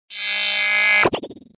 Звуки глюков
На этой странице собрана коллекция звуков цифровых глюков, сбоев и помех.